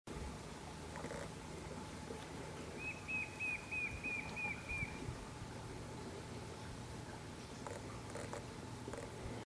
White-shouldered Fire-eye (Pyriglena leucoptera)
Life Stage: Adult
Location or protected area: Parque Federal Campo San Juan
Condition: Wild
Certainty: Recorded vocal
MVI_6594-batara-negro.mp3